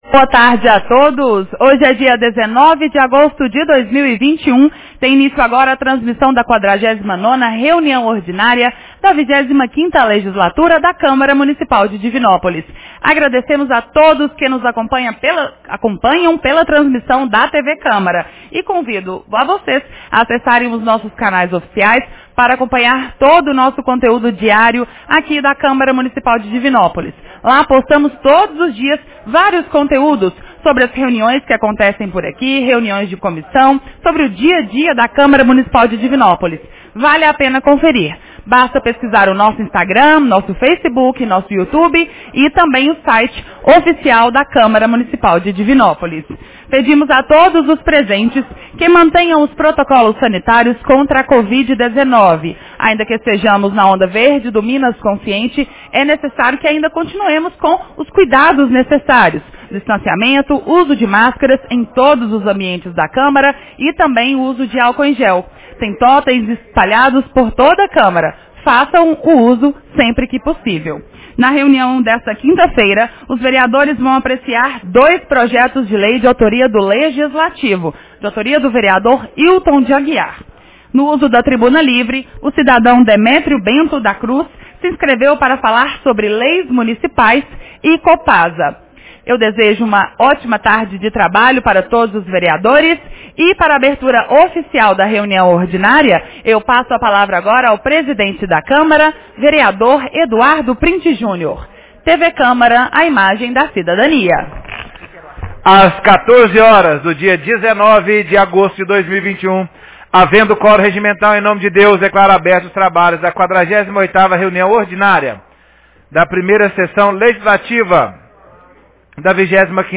Reunião Ordinária 49 de 19 de agosto 2021 — Câmara Municipal